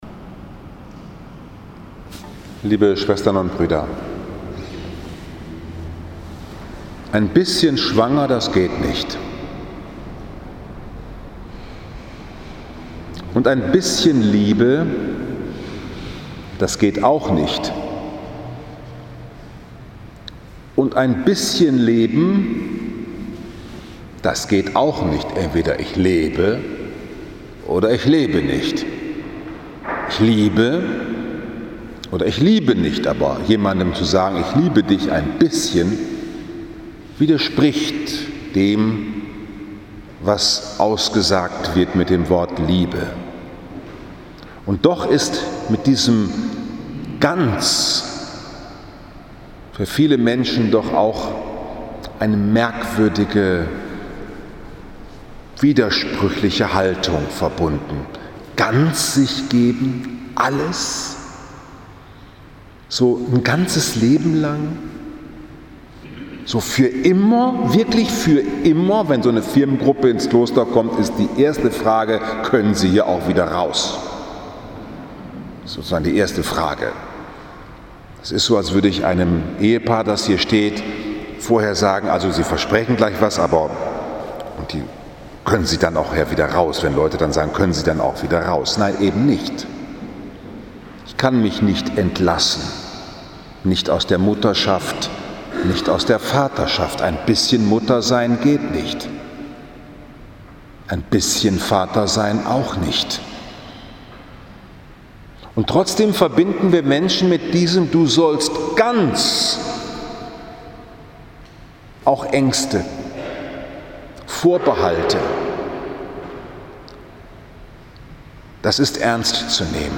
Die Angst vor dem Ganzen ~ Bruder Paulus´ Kapuzinerpredigt Podcast
8. September 2019, 20 Uhr, Liebfrauenkirche Frankfurt am Main, 23. So.i.J. C